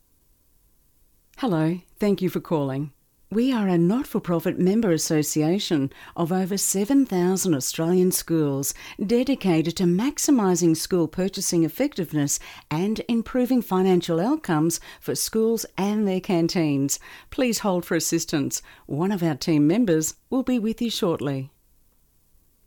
Professionally recorded phone messages
Male and female voice artists have clear enunciation and exceptional voice skills to convey your message in a friendly, pleasant manner.
Female voice - Style 2
Female-voice-artist-style-3.mp3